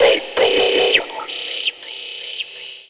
Index of /90_sSampleCDs/Zero-G - Total Drum Bass/Instruments - 3/track64 (Vox EFX)
05-Robotix.wav